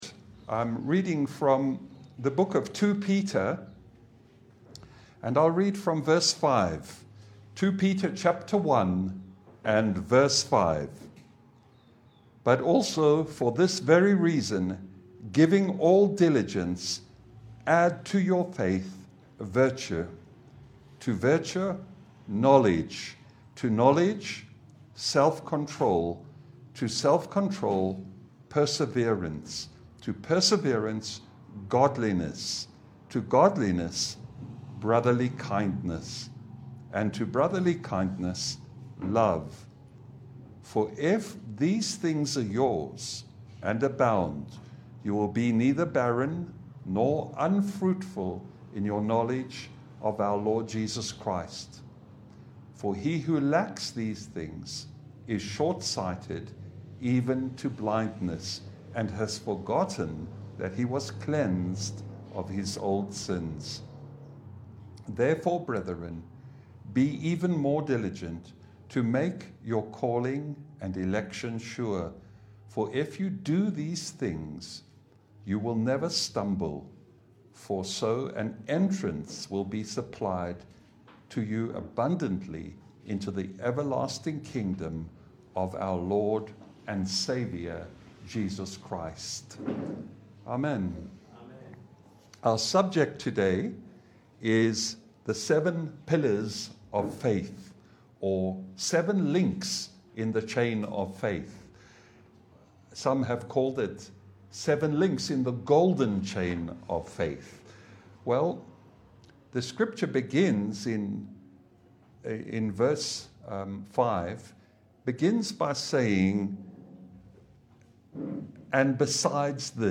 Passage: 2Peter1:1-5 Service Type: Sunday Bible fellowship